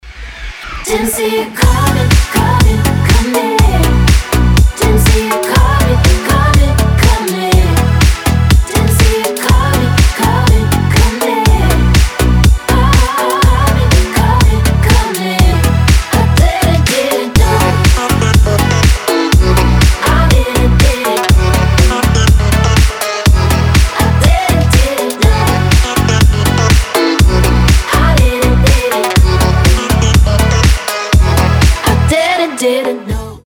• Качество: 320, Stereo
deep house
зажигательные
заводные
club
Заводная танцевальная мелодия в ремиксе